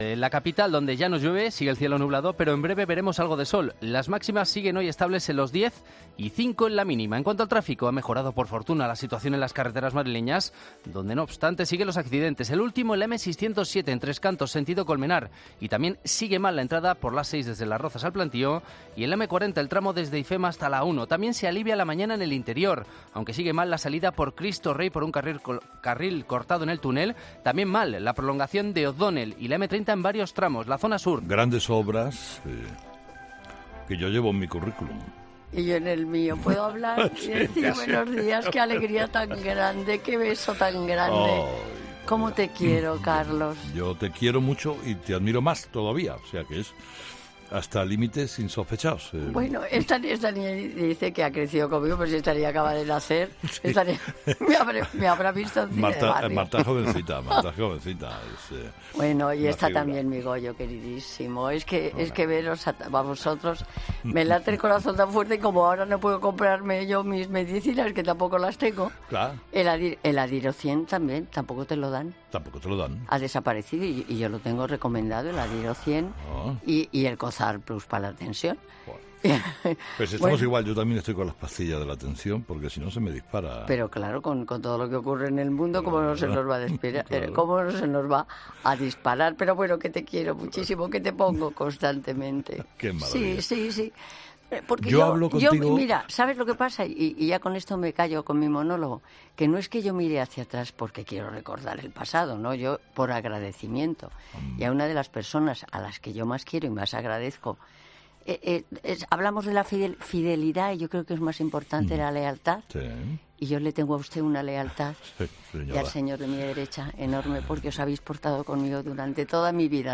Así empezaba una entrevista entre Carlos Herrera y Concha Velasco.
Termina este ratito de radio cantando, pero... ¿cómo quieren que lo transcriba?